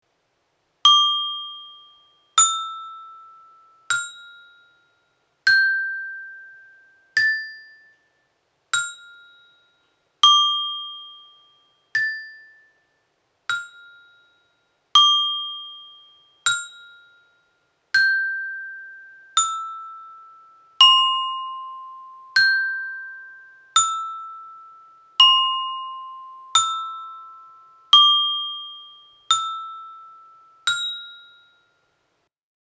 Ditado melódico